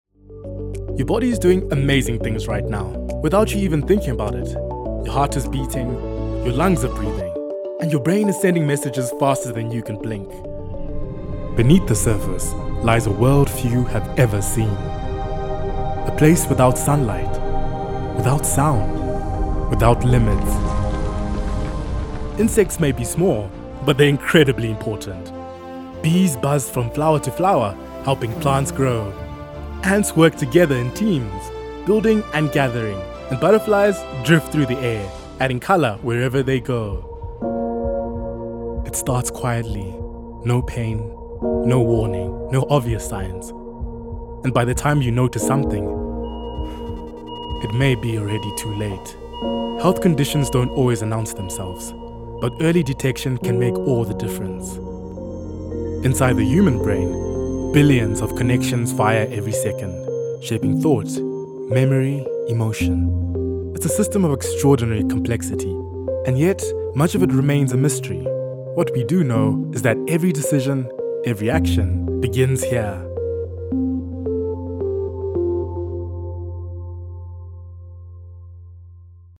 Narración
Micrófono de condensador Rode NT1
Adulto joven